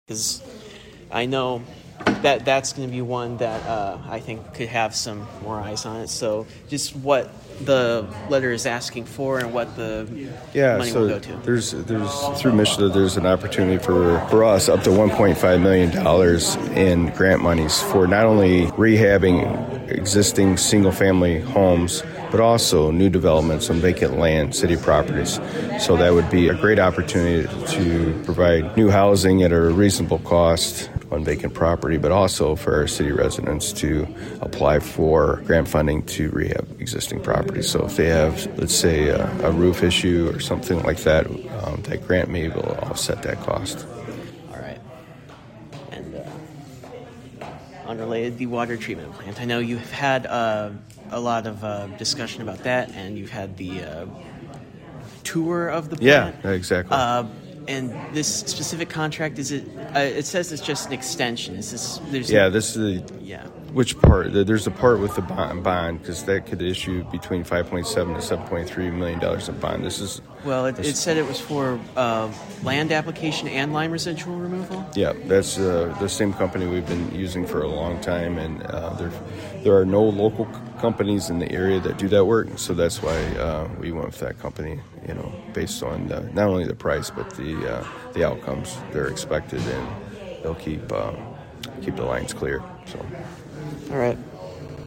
City Administrator Chad Baugh tells WLEN how much money Adrian would receive if their application is approved, and what it would go toward…